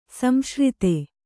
♪ samśrite